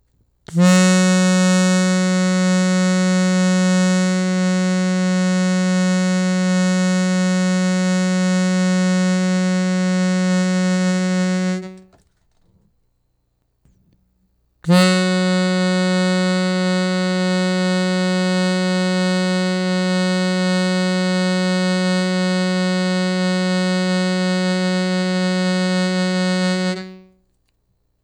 Reed's Pitch Sound(wav/4.9MB)-F#
reed.wav